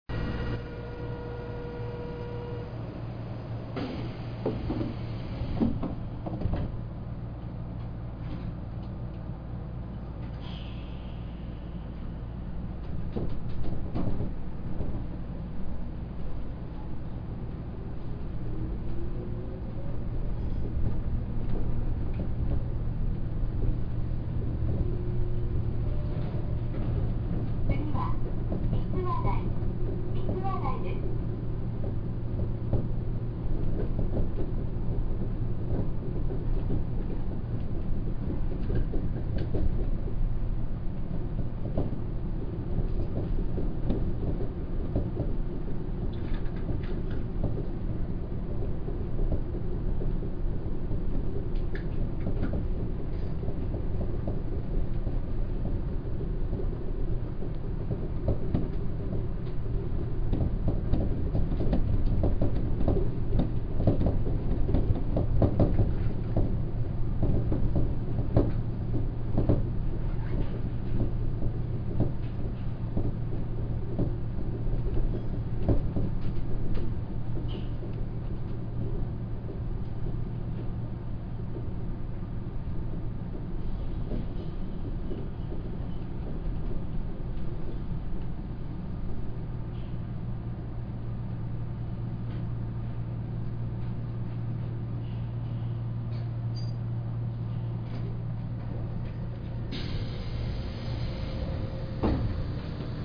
・1000形走行音
【2号線】動物公園→みつわ台（1分36秒）
懸垂形のモノレールってみんなこうなんでしょうか。走行音は全くと言っていいほど聞こえません。一応これでもモーター車に乗ったはずなのですが…。車内放送は3打点から始まる自動放送となります。